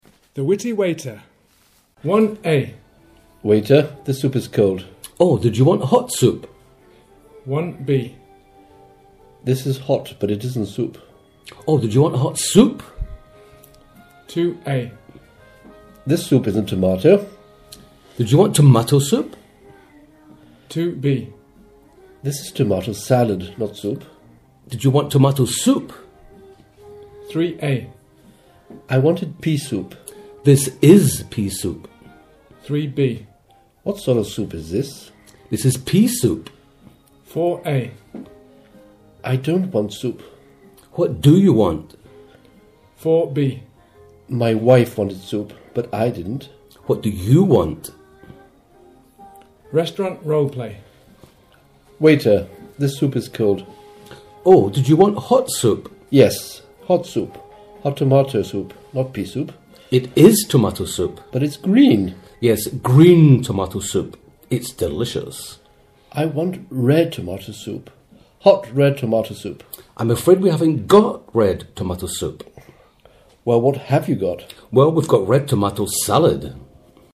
Pronunciation: contrastive stress
We see a waiter's responses to a complaining customer. The waiter can make the sentence mean something completely different according to the word he/she stresses...